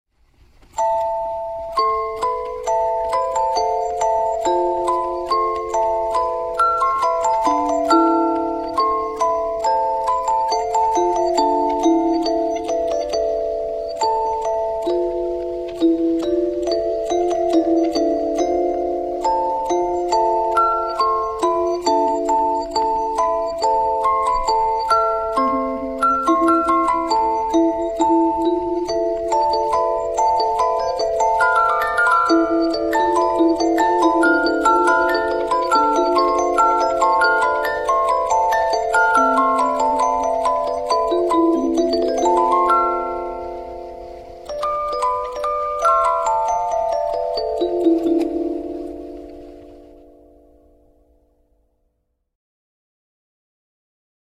実物オルゴールでの生録音は
日本の雰囲気としてはピカイチの曲ではないでしょうか。
和音階の曲って手回しのゆらぎといいますか陽炎のような“ゆれ”がとてもあっていて相性がいい気がしますね。